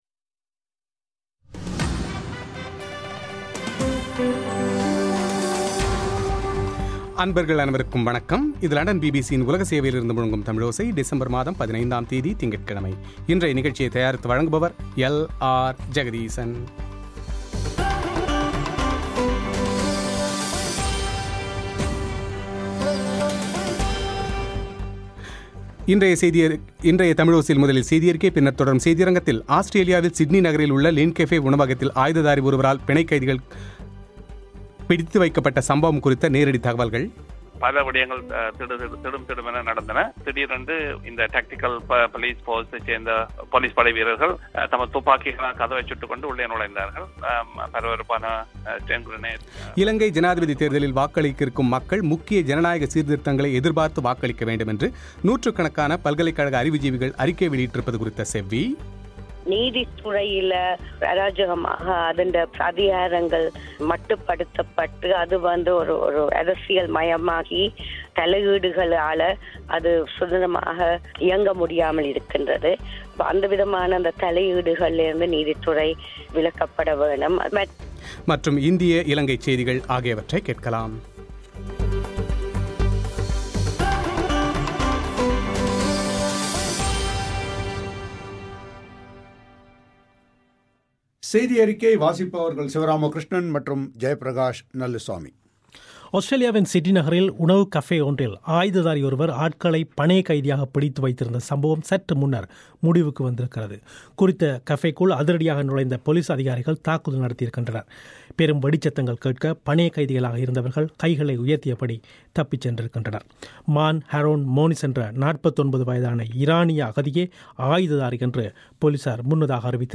முன்னாள் மத்திய அமைச்சர் நாராயணசாமியின் பேட்டி